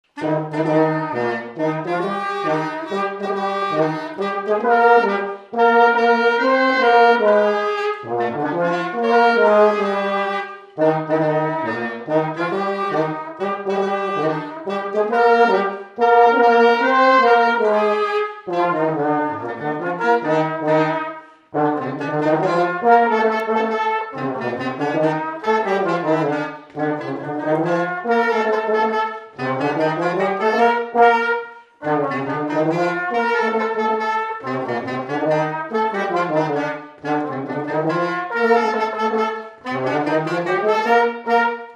Mazurka
Résumé instrumental
danse : mazurka
Pièce musicale inédite